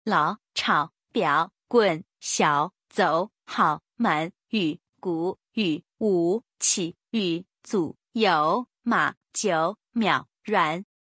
第三声の漢字と発音